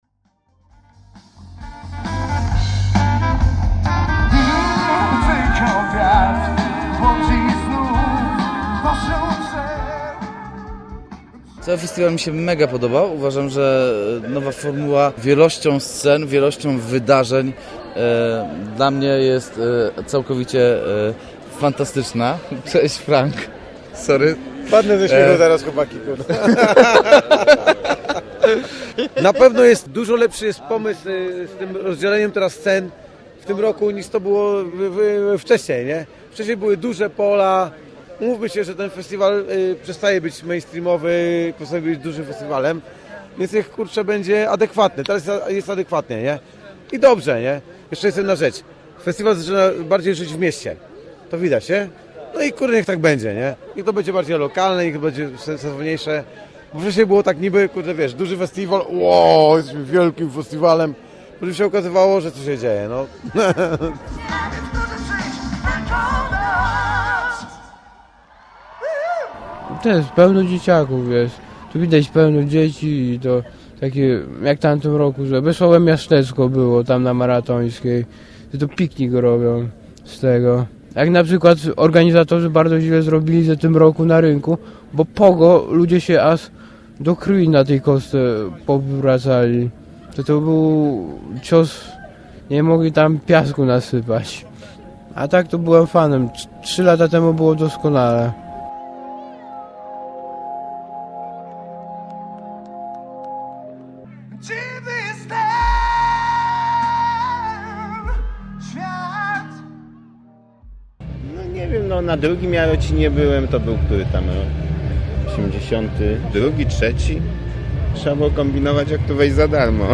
Reportaż z rockowego festiwalu w Jarocinie w 2017 roku.